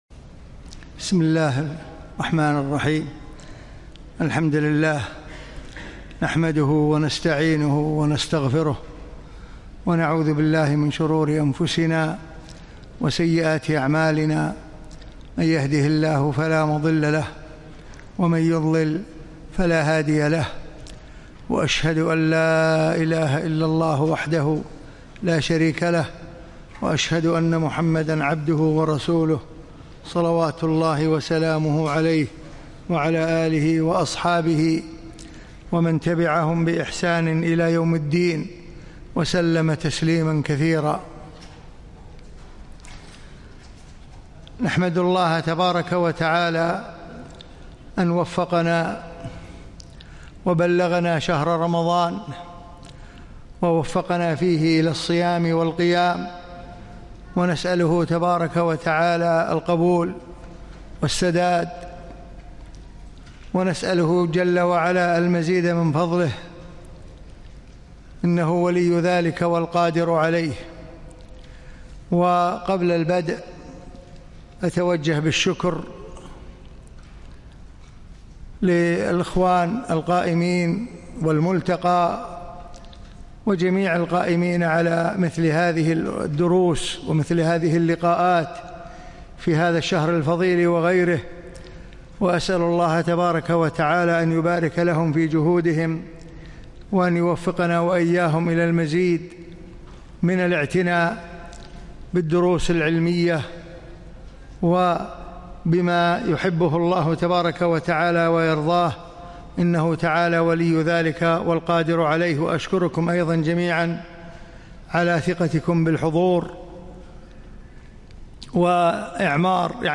من دروس الشيخ في دولة الإمارات لعام 1439
الصديق - محاضرة